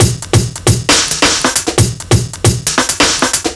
Shuffle Break 2 135.wav